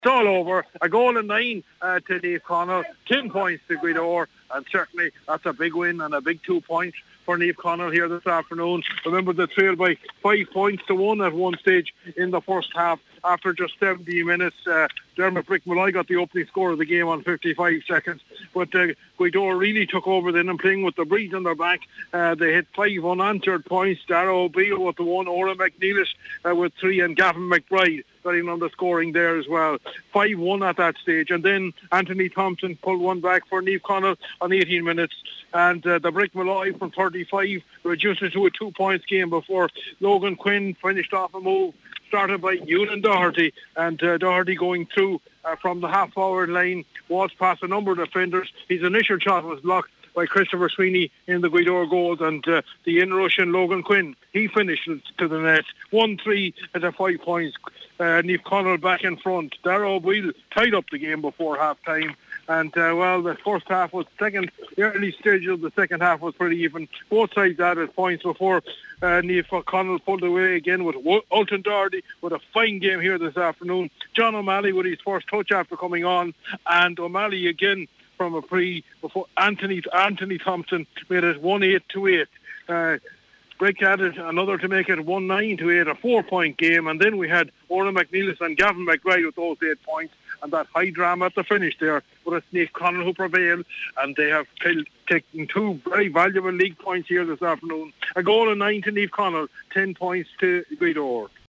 full-time report for Highland Radio Sport…